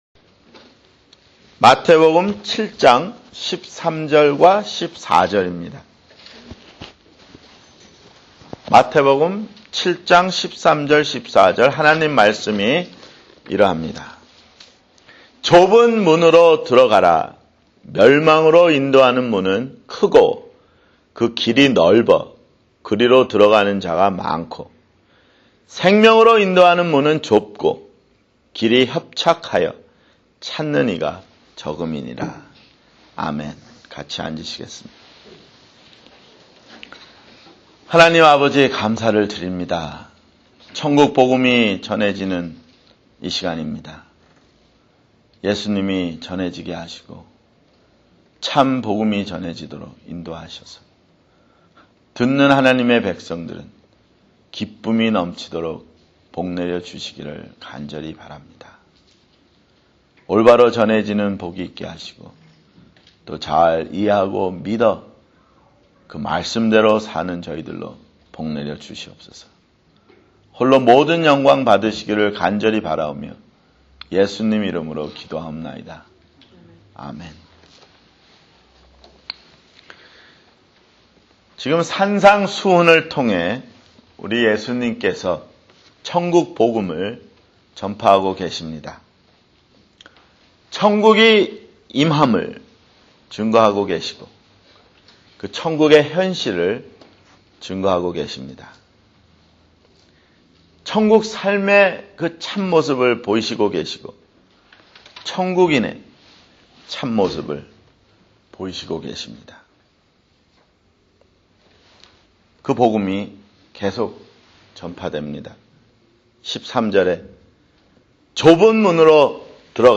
[주일설교] 마태복음 (45)